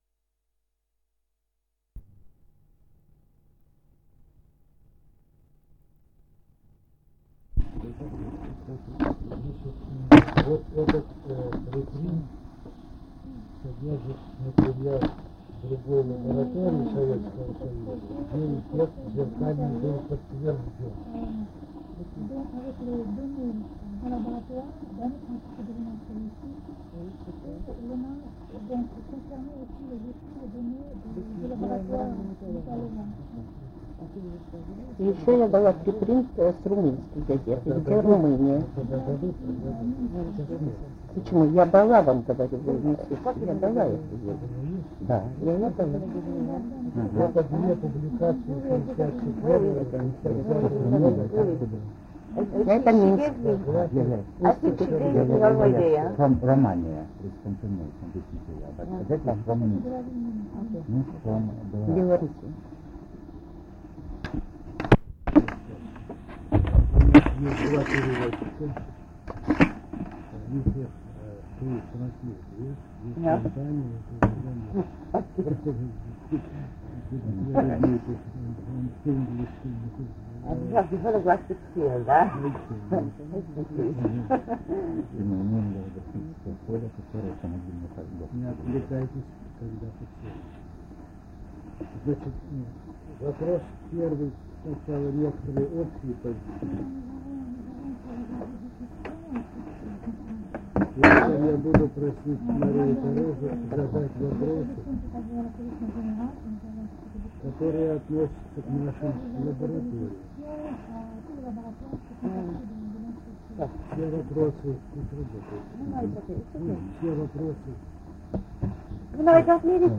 — 1 зв. диск (60 мин). - Устная речь.